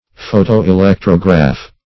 Search Result for " photo-electrograph" : The Collaborative International Dictionary of English v.0.48: Photo-electrograph \Pho`to-e*lec"tro*graph\, n. [See Photo- ; Electrograph .]